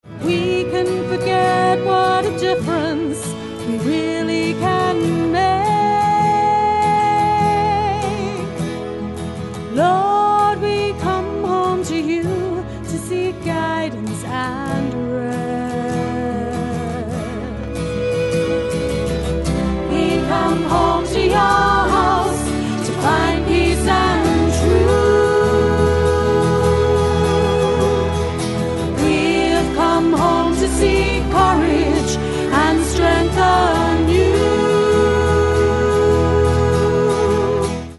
A communion hymn written in ¾ time.